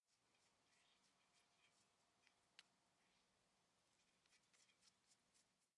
斯坦福自行车 " Ai'valve - 声音 - 淘声网 - 免费音效素材资源|视频游戏配乐下载
轻轻地打开，然后关闭自行车轮胎的气阀。
录制于斯坦福大学校园，9/5/09星期六。